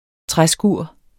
Udtale [ ˈtʁaˌsguɐ̯ˀ ]